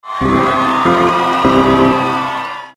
Звуковое оповещение о платной подписке